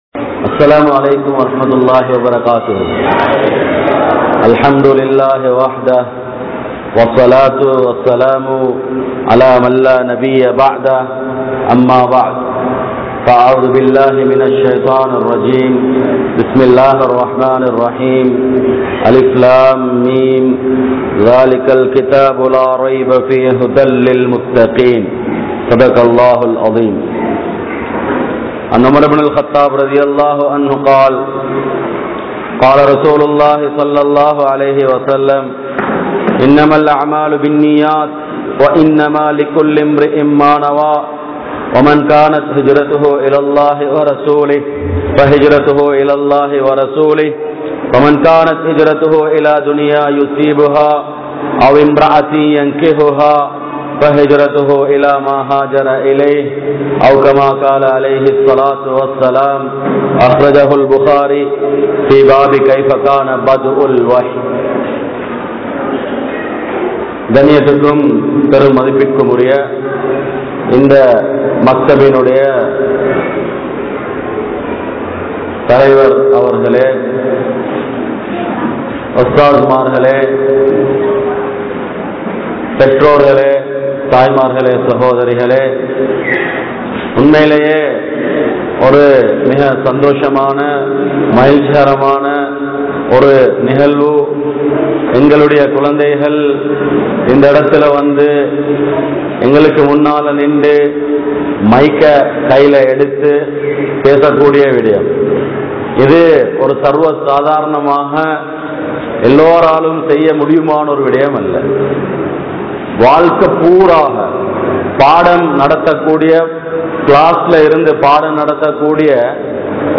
Kulanthaiahali Seeralippathu Petroarhala? (குழந்தைகளை சீரழிப்பது பெற்றோர்களா?) | Audio Bayans | All Ceylon Muslim Youth Community | Addalaichenai
Galle, Gintota, Zahira College